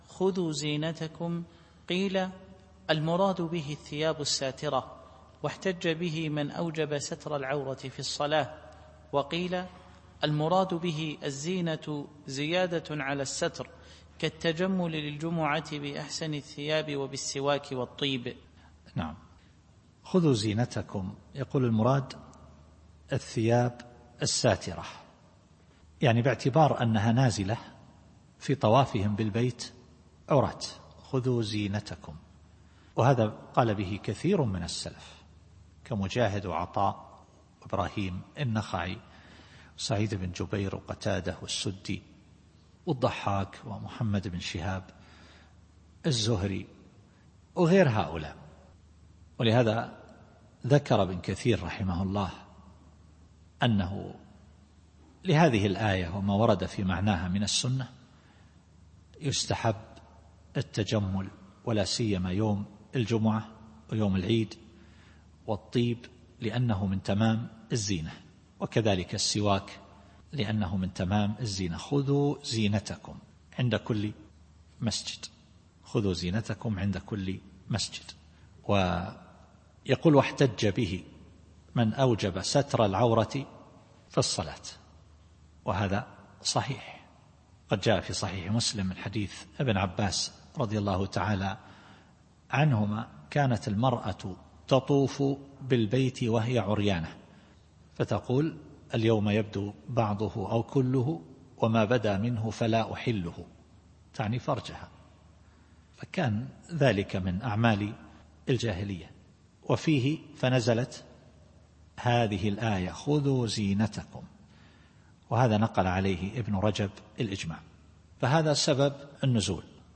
التفسير الصوتي [الأعراف / 31]